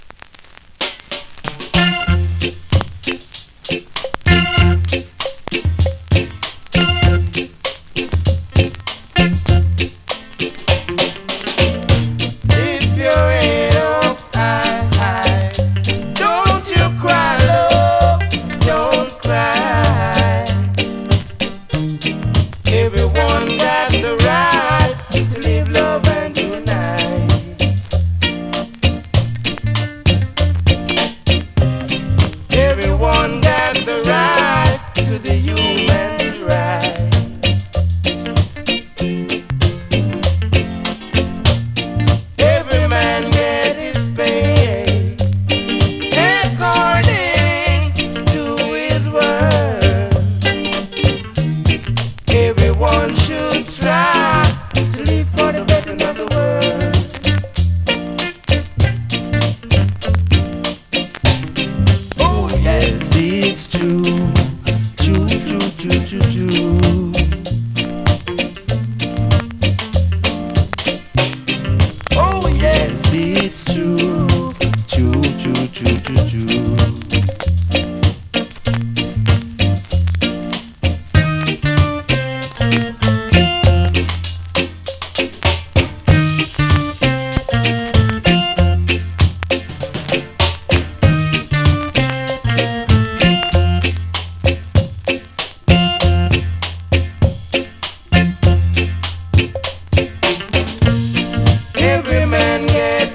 2026!! NEW IN!SKA〜REGGAE
スリキズ、ノイズそこそこありますが